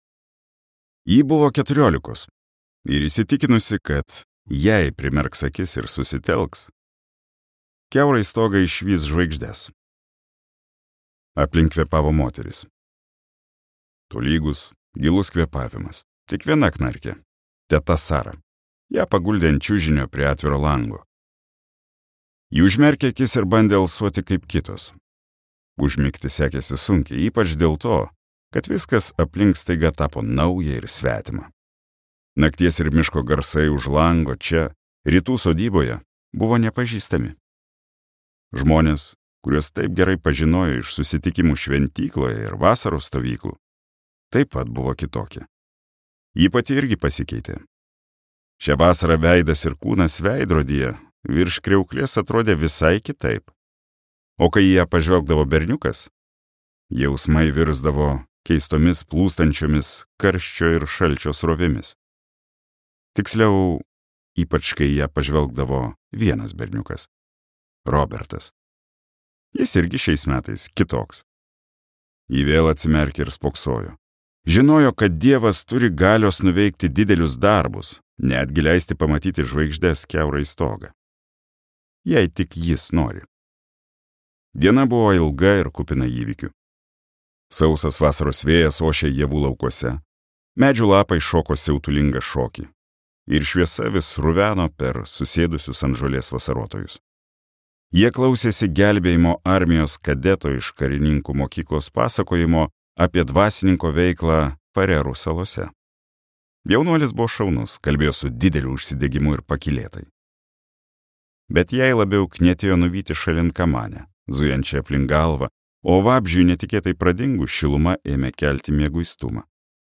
Gelbėtojas | Audioknygos | baltos lankos
Jo Nesbo audioknyga „Gelbėtojas“